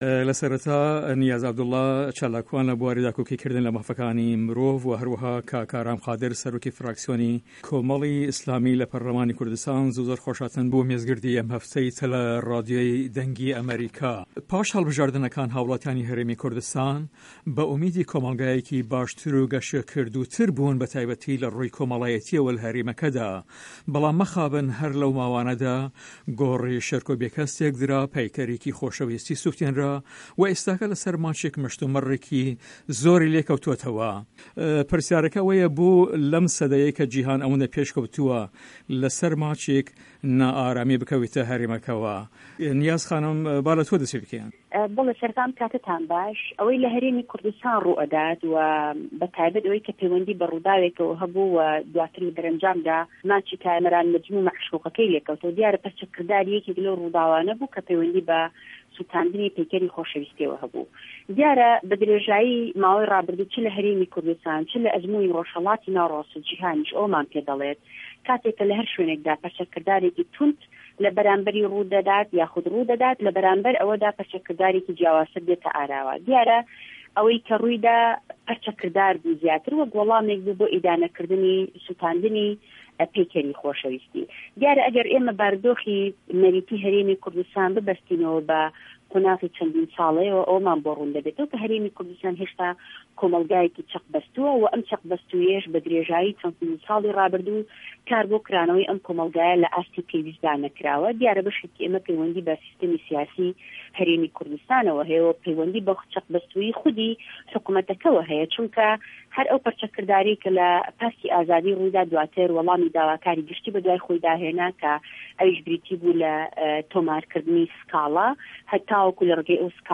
مێزگردی هه‌فته‌: بۆله‌سه‌رماچێک نا ئارامی بکه‌وێته‌ هه‌رێمه‌که‌وه‌؟
Round Table 10/26/13